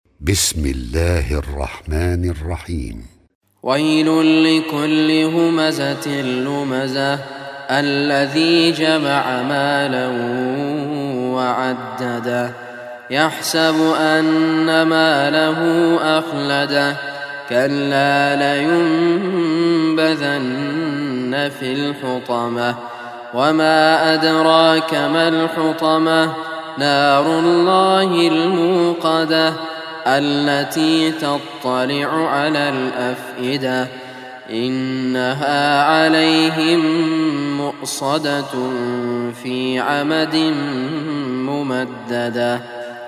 Surah Al Humazah Online Recitation by Raad Kurdi
Surah Al Humazah, listen or play online mp3 tilawat/ recitation in Arabic in the beautiful voice of Sheikh Raad Kurdi.